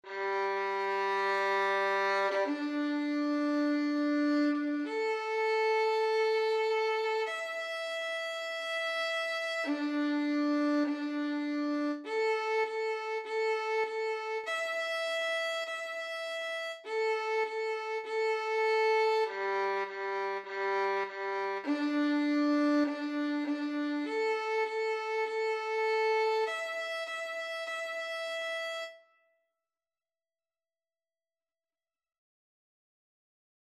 4/4 (View more 4/4 Music)
Instrument:
Classical (View more Classical Violin Music)